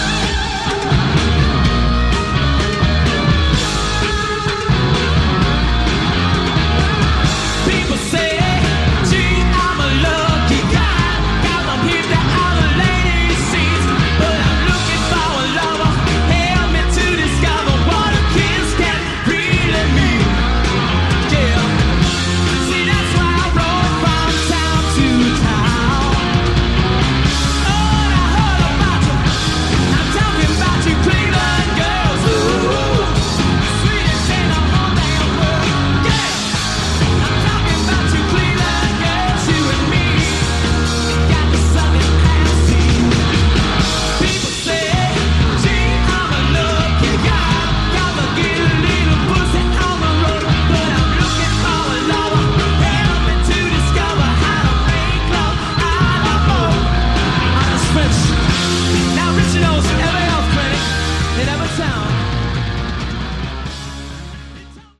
Category: Hard Rock
lead vocals
guitar
bass